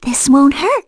Mediana-Vox_Skill4.wav